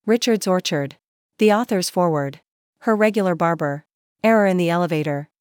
LET US PHRASE IT 複数の英単語で発音練習